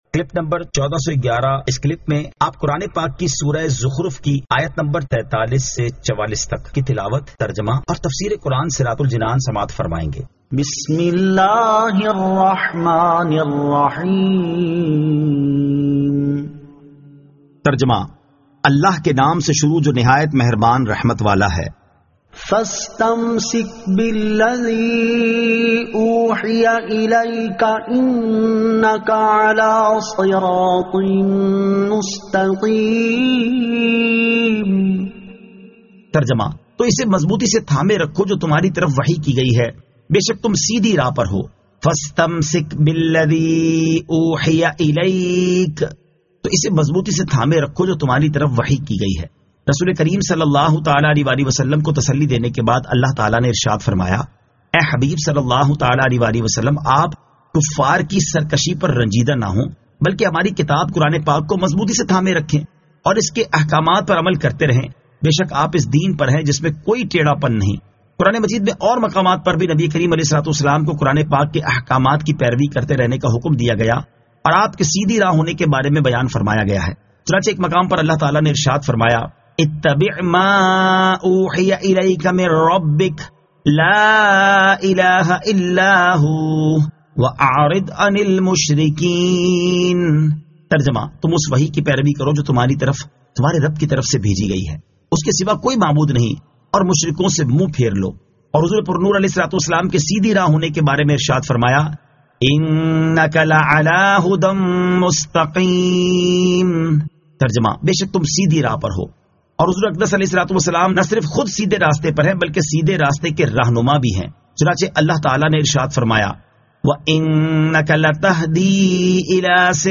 Surah Az-Zukhruf 43 To 44 Tilawat , Tarjama , Tafseer
2023 MP3 MP4 MP4 Share سُوَّرۃُ الزُّخٗرُف آیت 43 تا 44 تلاوت ، ترجمہ ، تفسیر ۔